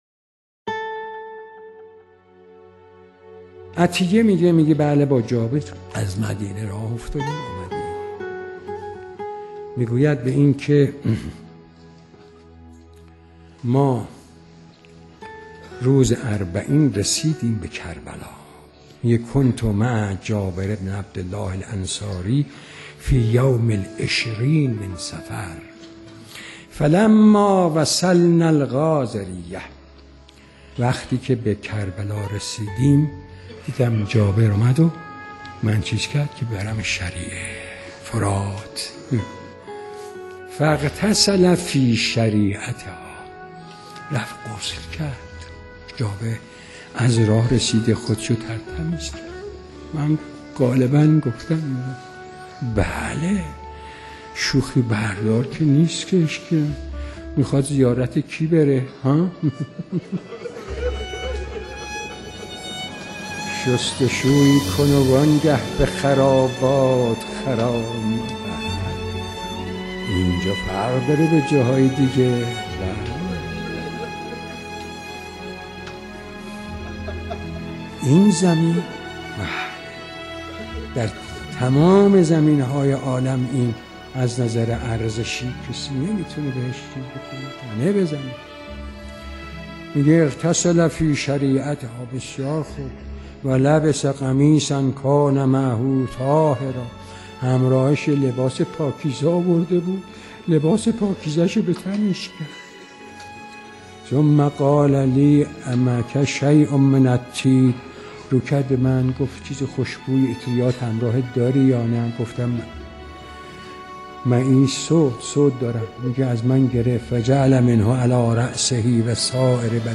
روضه-اربعین-حاج-آقا-مجتبی-تهرانی.mp3